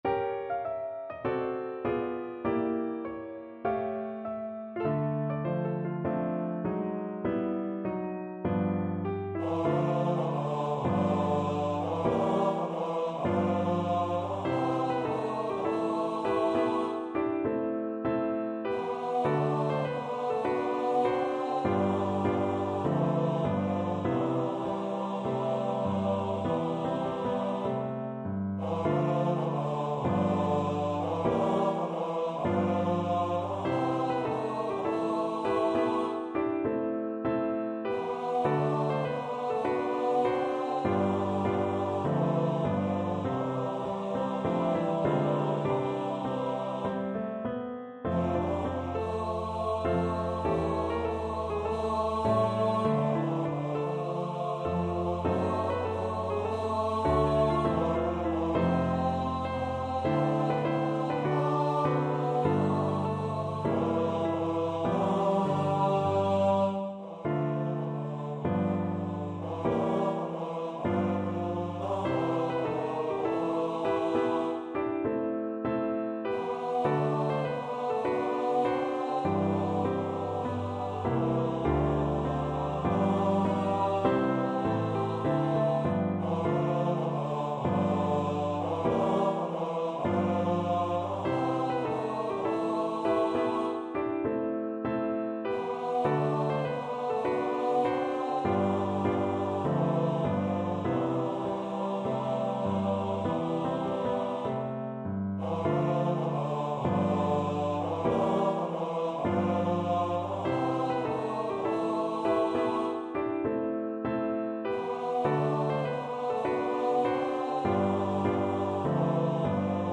AS TIME GOES BY - Rame Peninsula Male Voice Choir